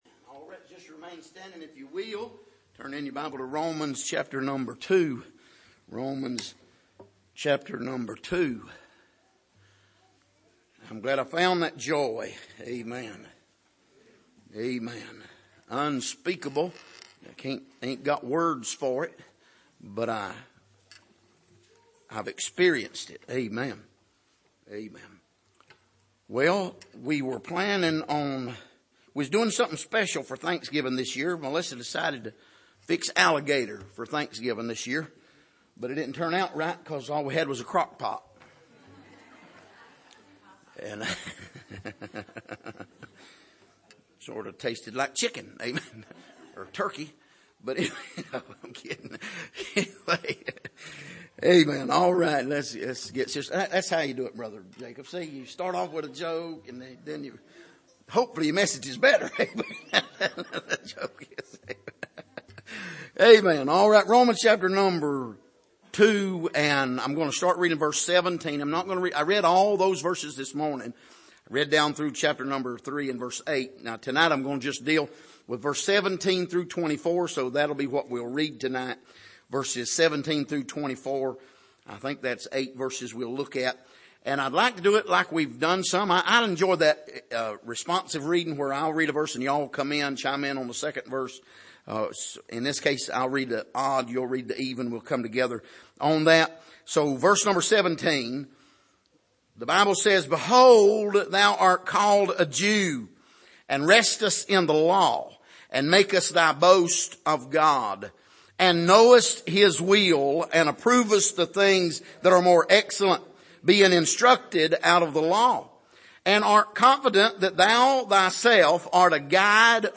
Passage: Romans 2:17-24 Service: Sunday Morning